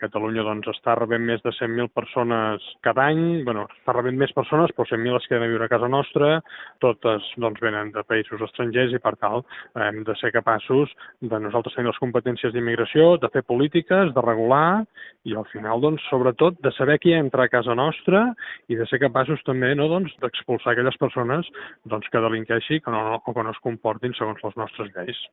L’alcalde de Calella, Marc Buch (Junts), celebra l’acord Junts-PSOE per delegar les competències en matèria d’immigració a Catalunya perquè implica assolir una major quota d’autogovern però també tenir el control fronterer, d’entrada i sortida de persones, i poder “expulsar” les que delinqueixin. Són declaracions a RCT.